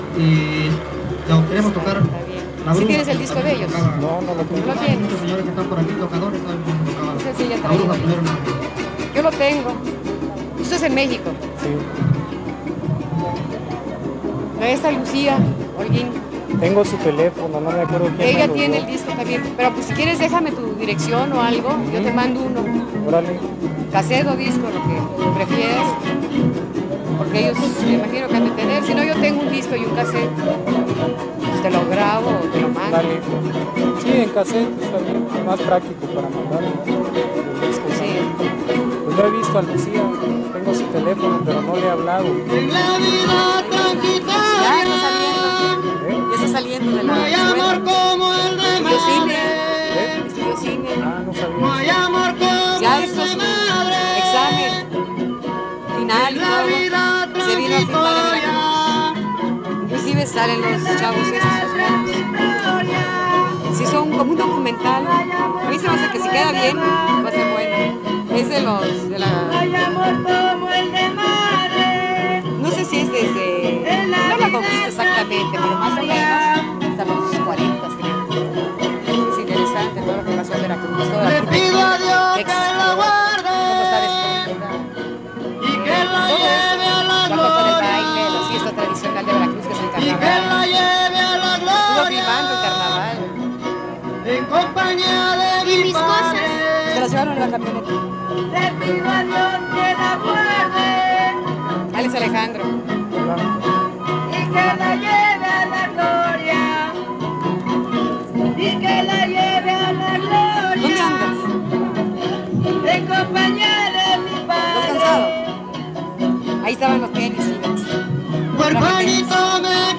• Cultivadores del son (Grupo musical)
Encuentro de son y huapango